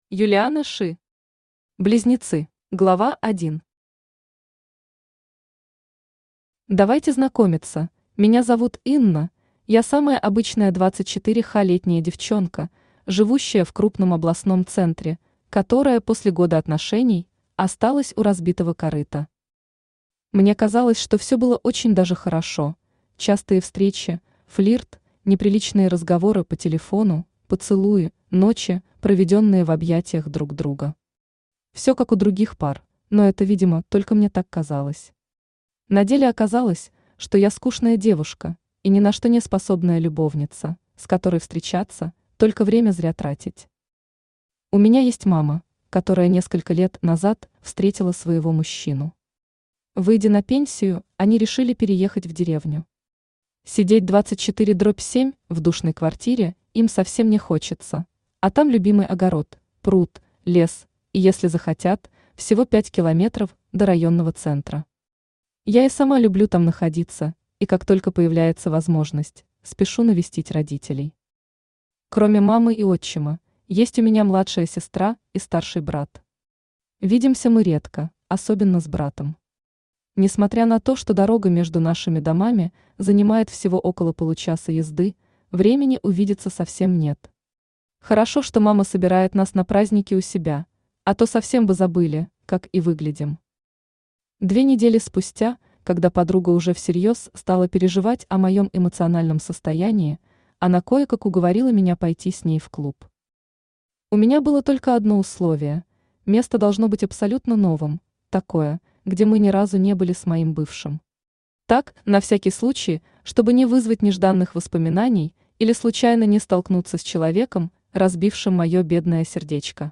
Aудиокнига Близнецы Автор Юлиана Ши Читает аудиокнигу Авточтец ЛитРес.